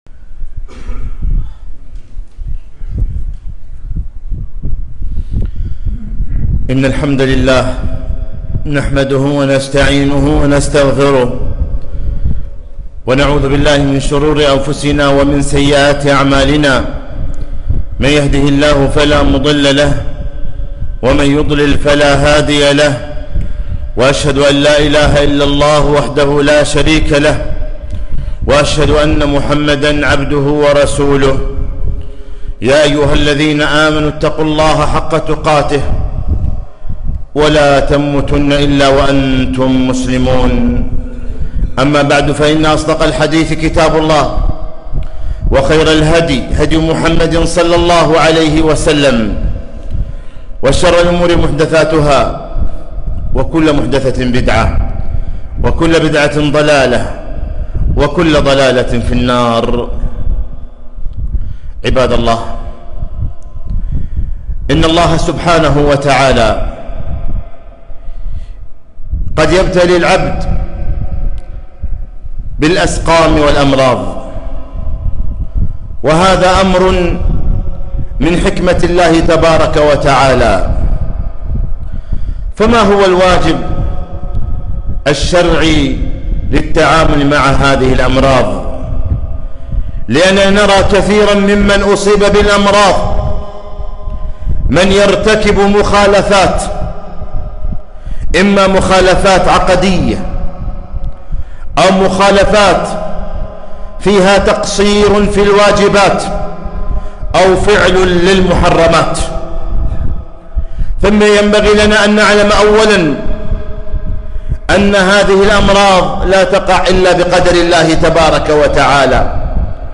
خطبة - كيف نتعامل مع الأمراض ؟